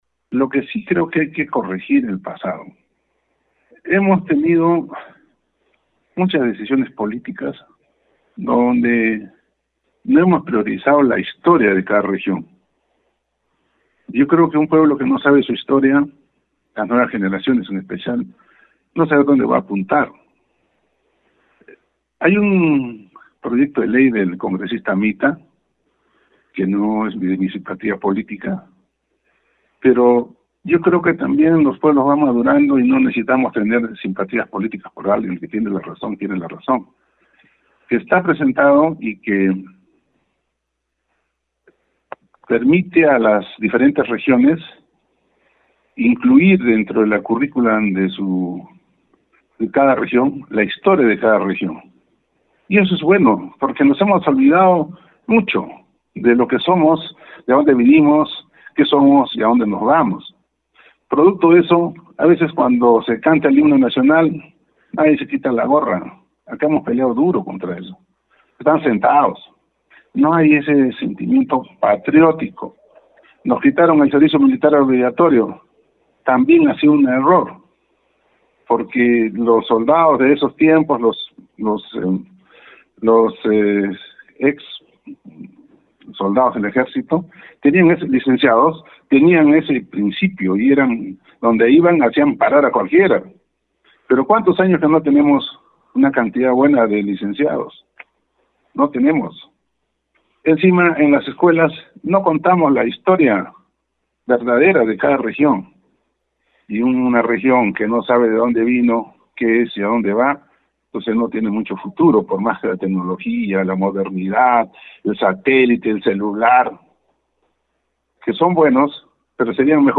En el marco de la ceremonia que conmemora la firma del acta de entrega de Tacna al Perú, el gobernador regional Luis Torres anunció que presentará una ordenanza ante su concejo para que la historia de la región de Tacna sea incluida de manera obligatoria en la currícula educativa a partir del próximo año.